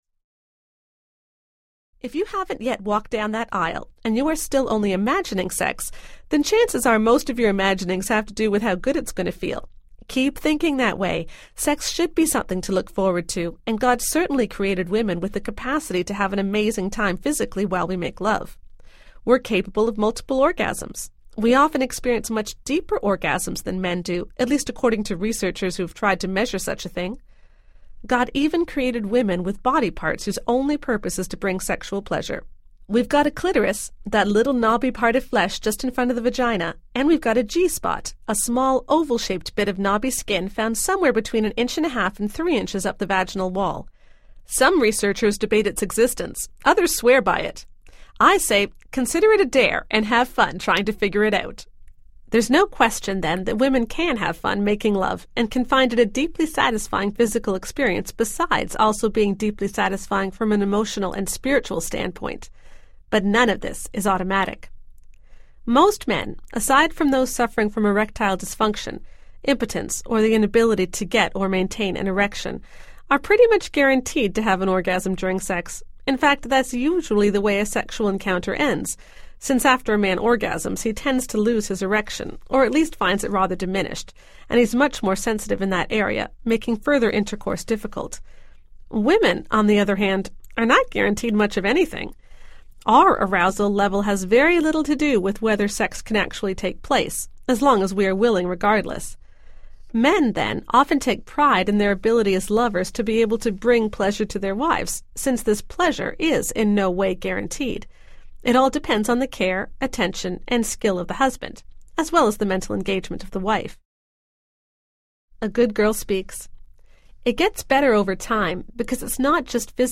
The Good Girl’s Guide to Great Sex Audiobook
7.15 Hrs. – Unabridged